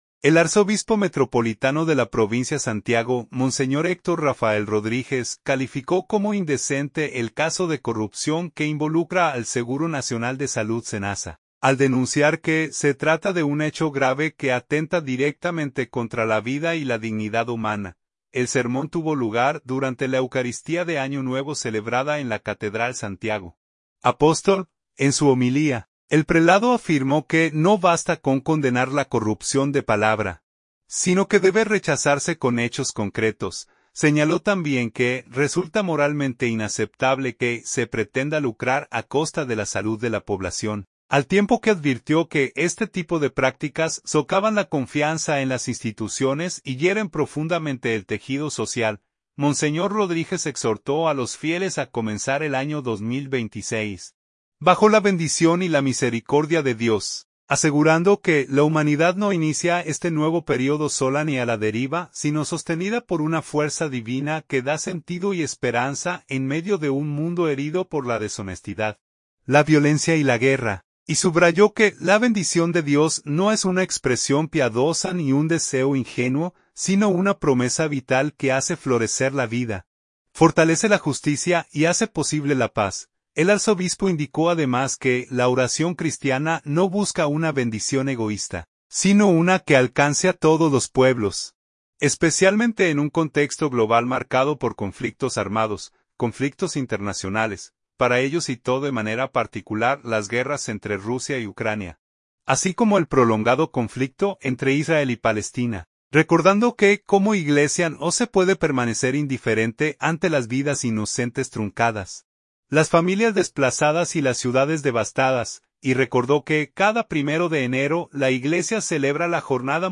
El sermón tuvo lugar durante la eucaristía de Año Nuevo celebrada en la Catedral Santiago Apóstol.